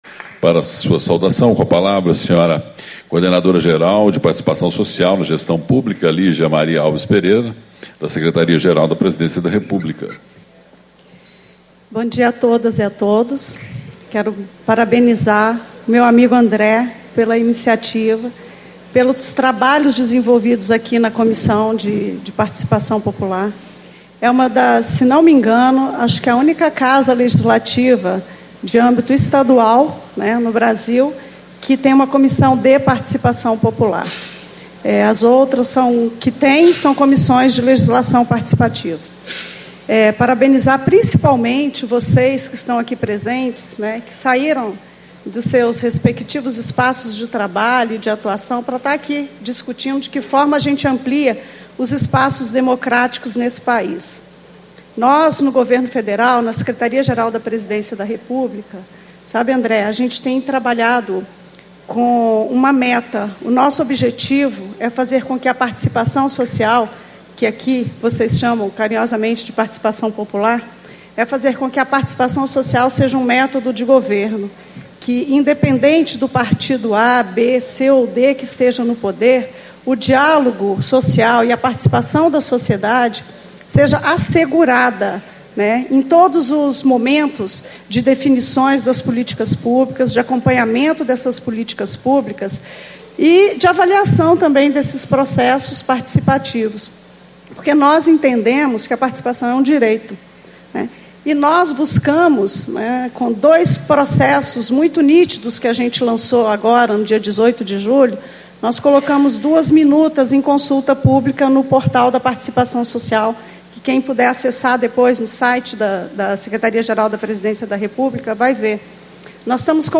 Discursos e Palestras